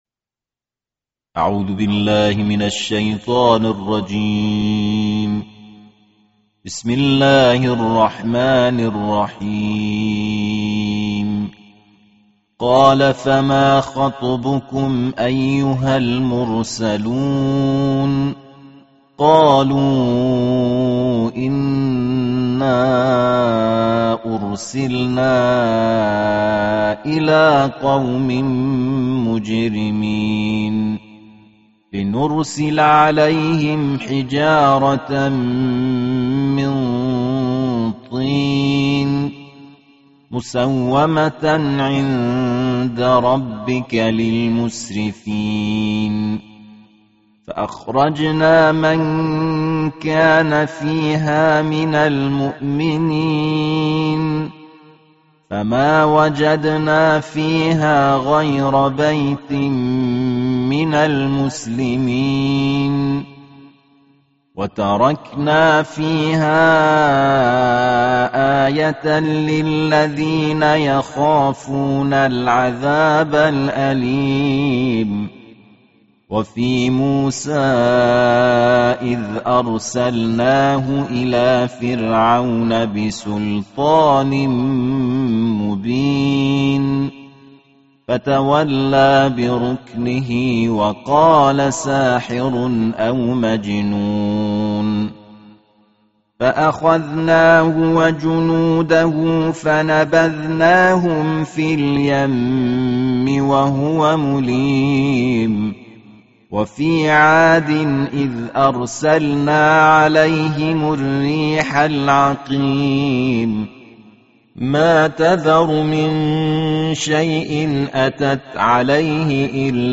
Tartil